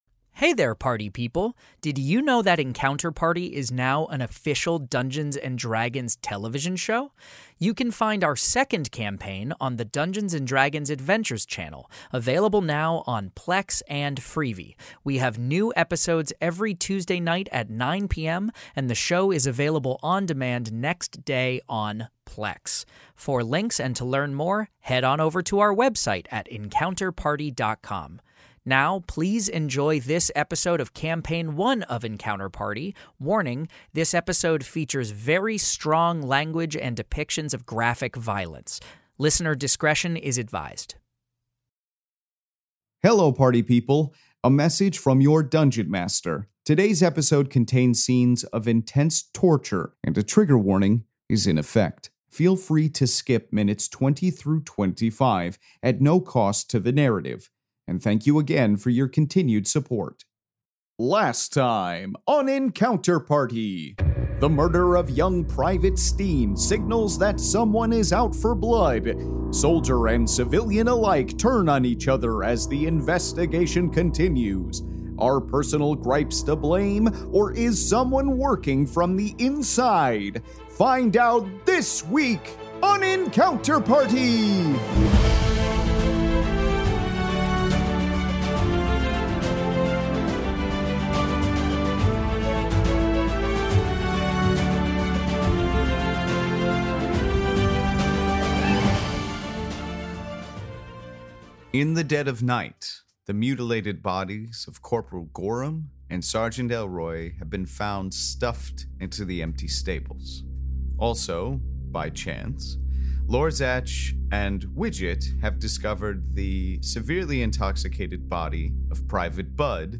Fantasy Mystery Audio Adventure
leads five actors and comedians through an epic tale of action, adventure, and intrigue!